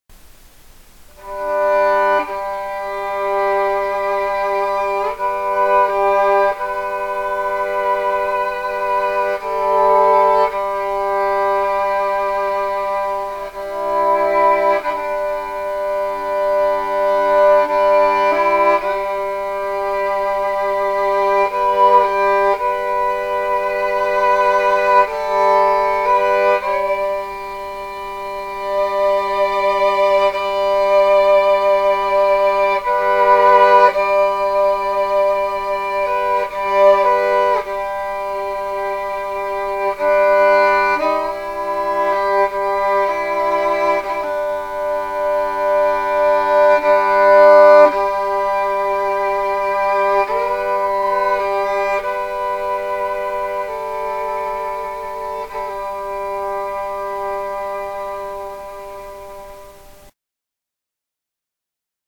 ON THE VIOLIN!
Amazing Grace - "Bagpipes Version" First Time Through (984k)